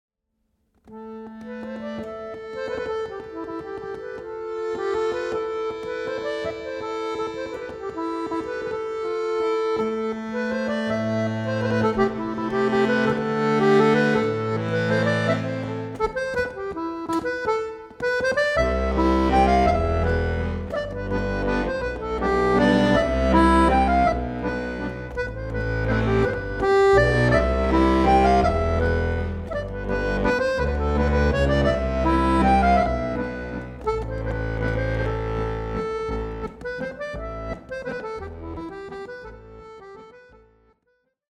Free-bass accordion, stomp-box, and singing